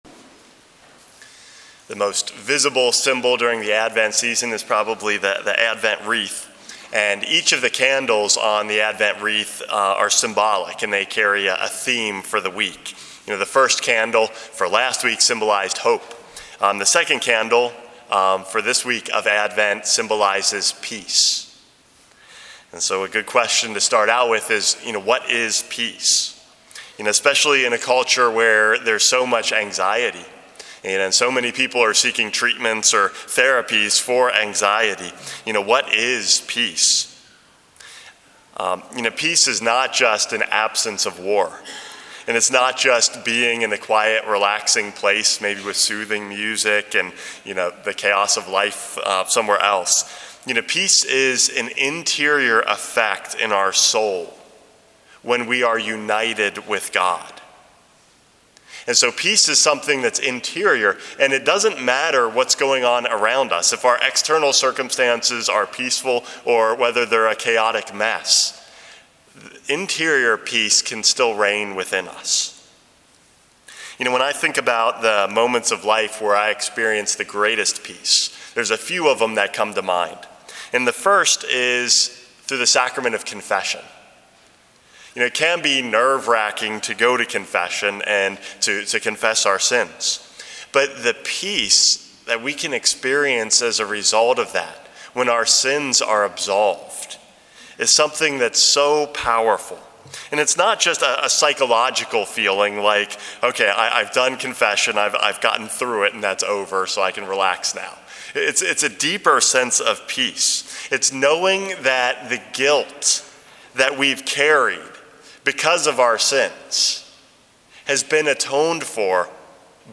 Homily #427 - The Second Candle